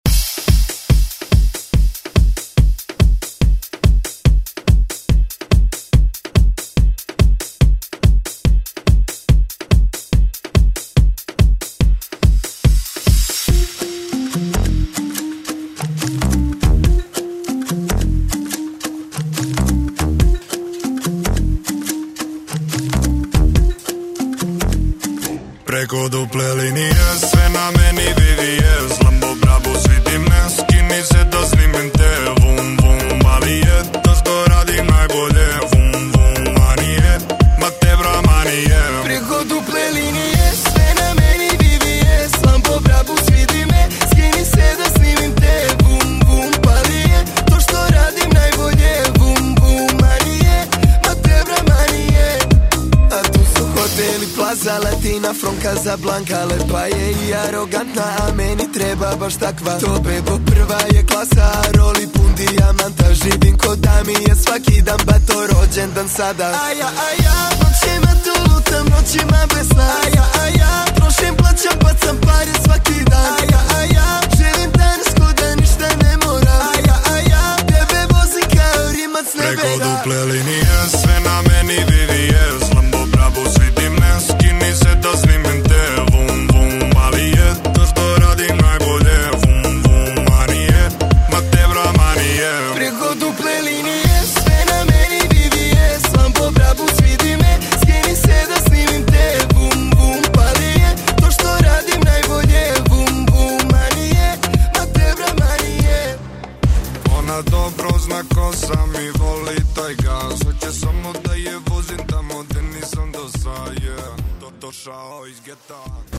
Latin Pop Music Extended ReDrum Clean 101 bpm
Genres: LATIN , RE-DRUM , REGGAETON
Clean BPM: 101 Time